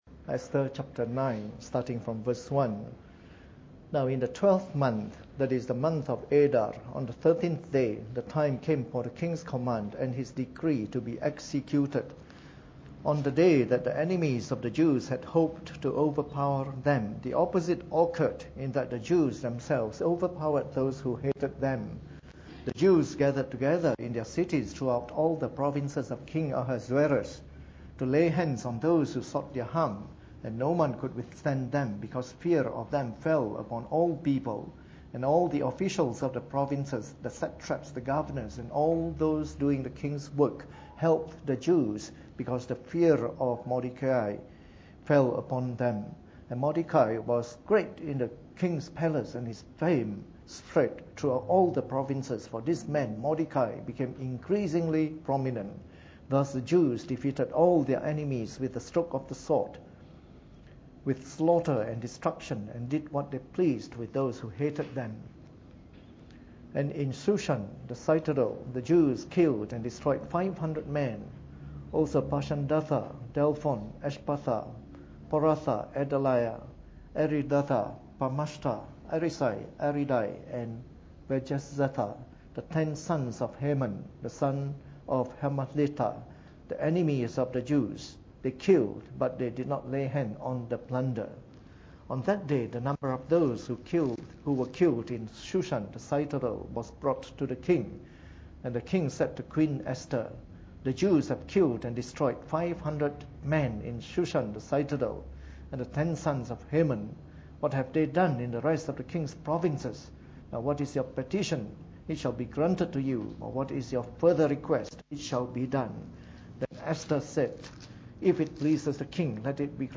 Preached on the 27th of November 2013 during the Bible Study, from our series of talks on the Book of Esther.